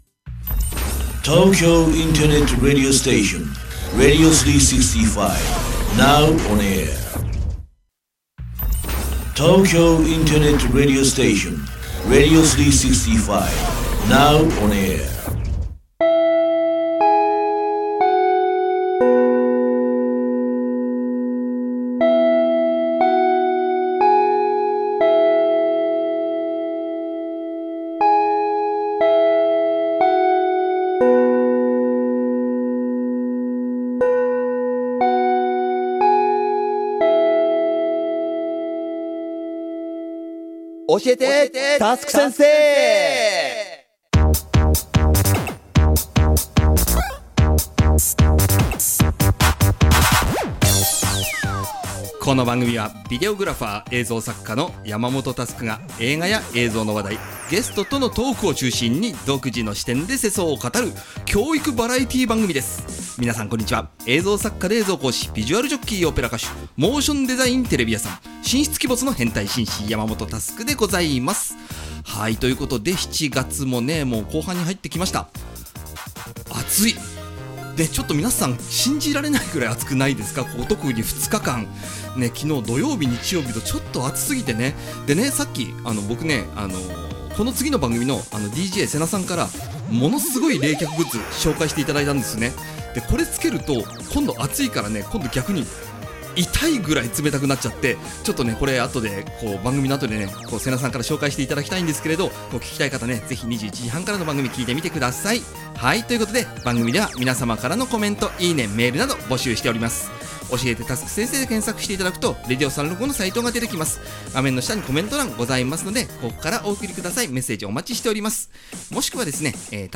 Vol.60～2021年7月18日生放送アーカイブ